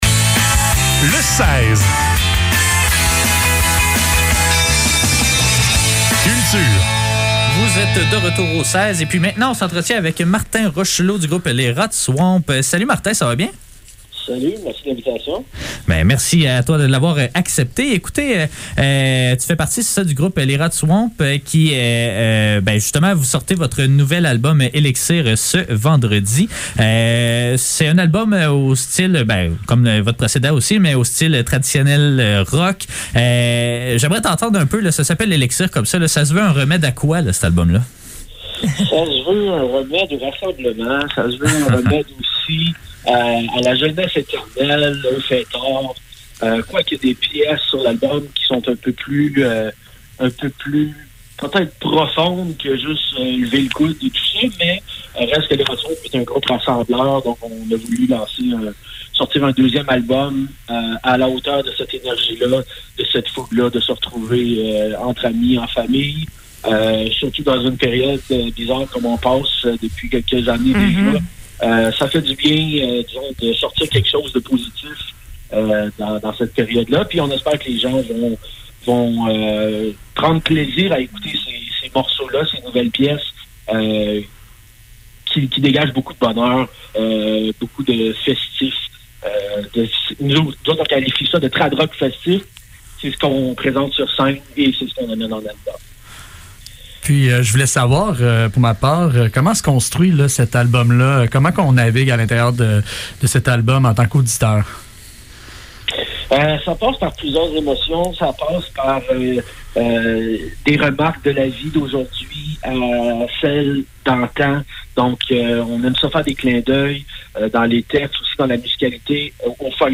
Entrevue-avec-Les-rats-d-swompe.mp3